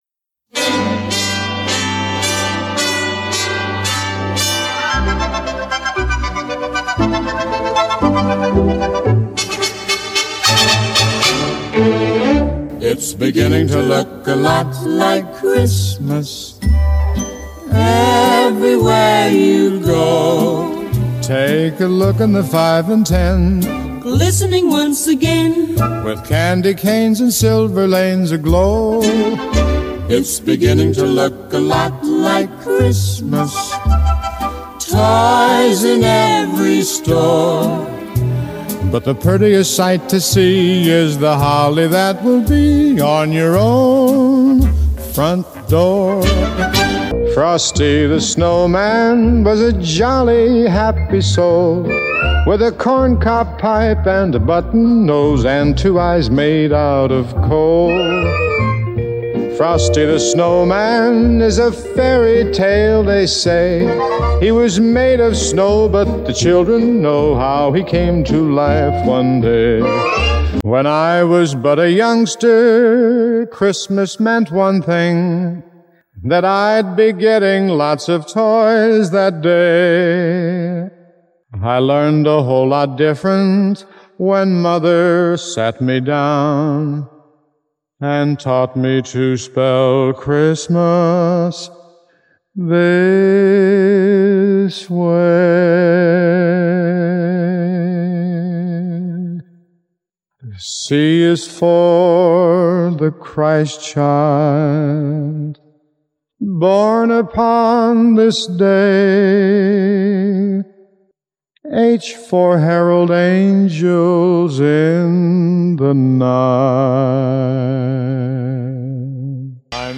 His voice is so smooth and beautiful!
C-H-R-I-S-T-M-A-S (A cappella)